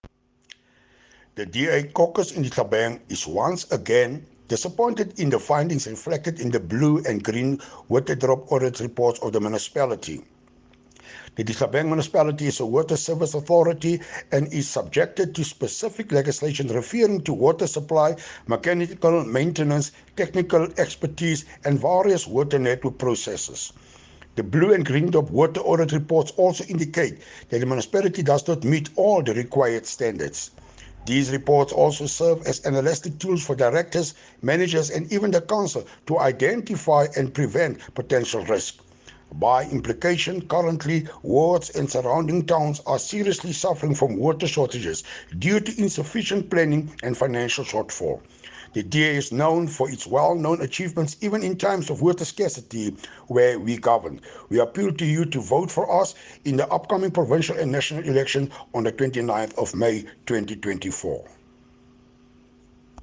Afrikaans soundbites by Cllr Hilton Maasdorp and Sesotho by Karabo Khakhau MP.